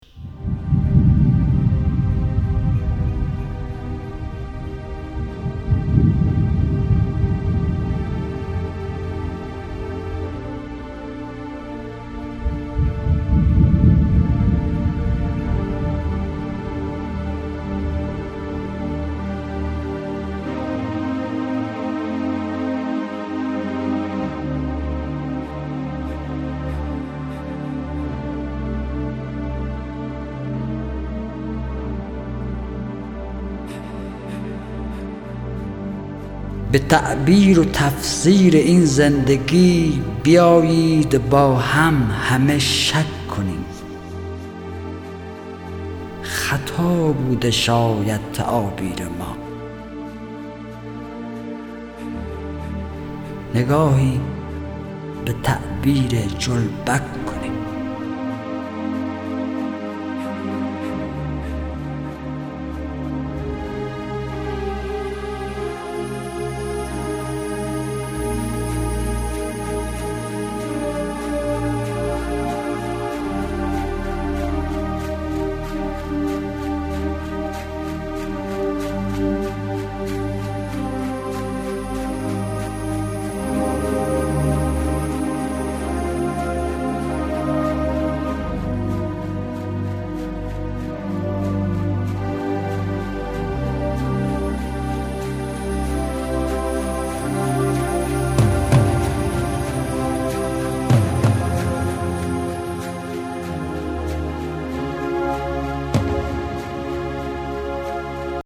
دانلود دکلمه جلبکانه با صدای حسین پناهی
گوینده :   [حسین پناهی]